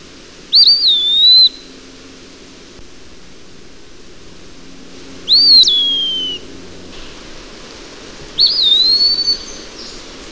Eastern Wood-Pewee
Contopus virens